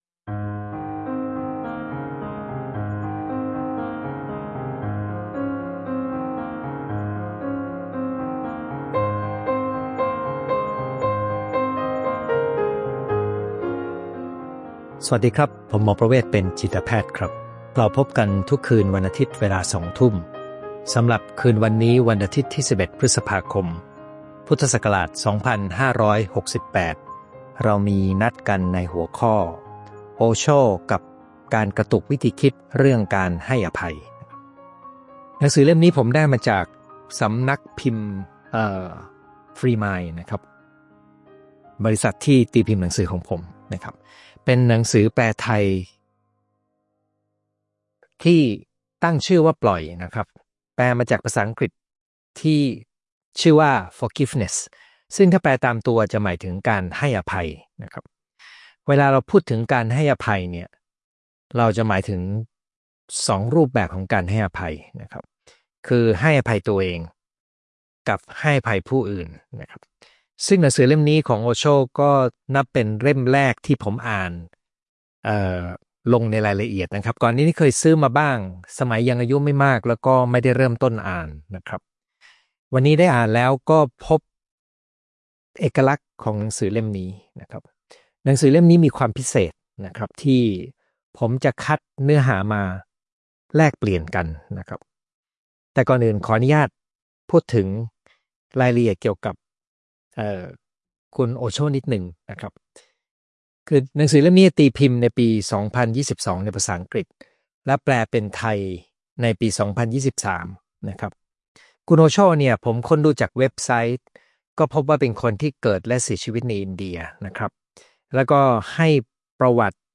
Facebook LIVE